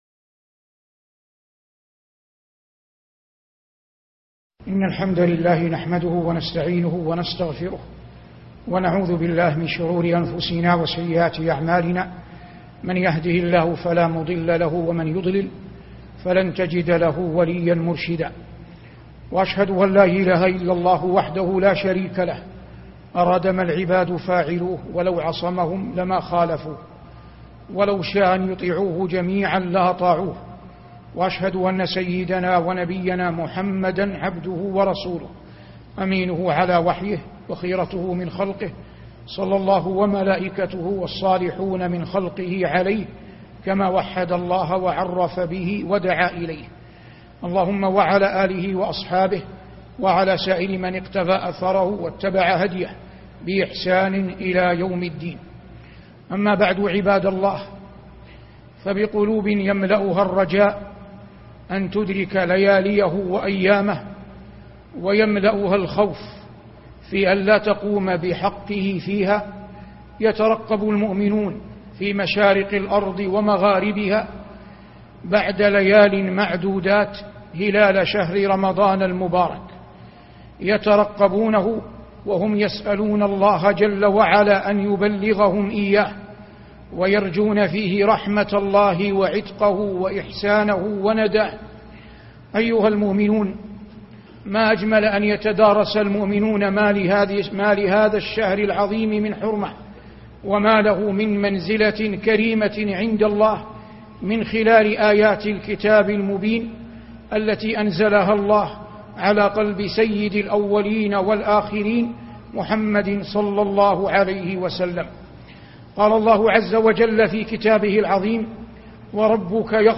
فضل مضان والعتق من النيران ( 23/8/1433)خطب الجمعة - الشيخ صالح بن عواد المغامسى